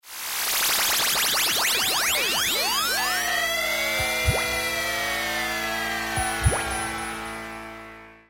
EMS Synthi AKS
Demo Excerpt (Vintage Synths)
ems_synthiaks_vintagesynths_excerpt1.mp3